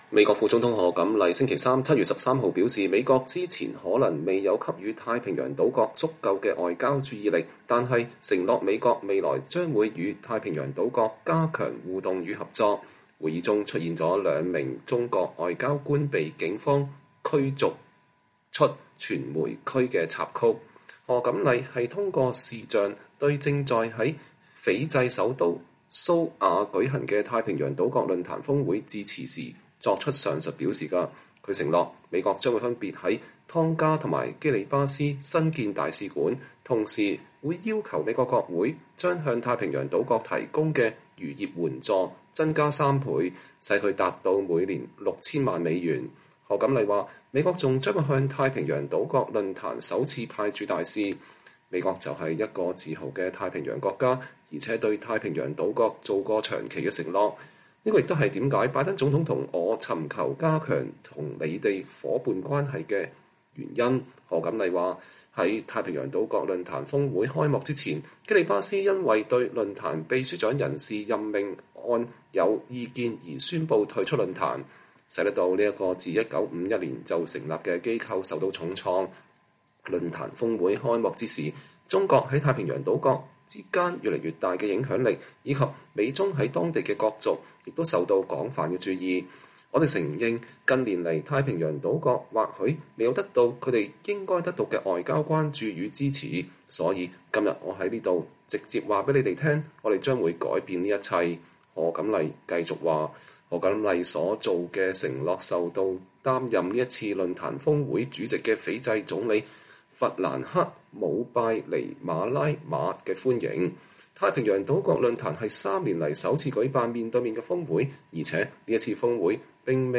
美國副總統賀錦麗向太平洋島國論壇發表視頻演講，表示要加強美國與這些國家的互動與合作。